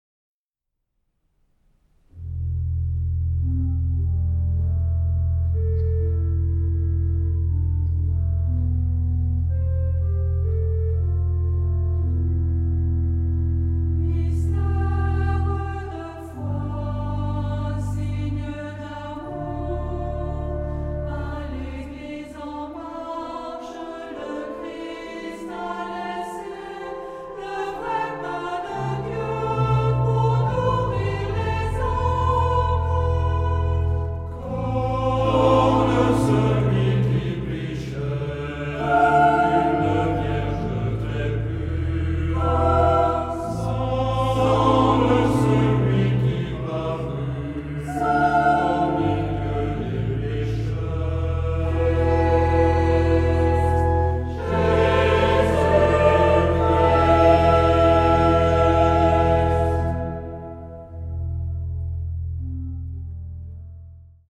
Genre-Style-Form: Sacred ; Hymn (sacred)
Mood of the piece: calm
Type of Choir: SAH  (3 mixed voices )
Instruments: Organ (1)
Tonality: E tonal center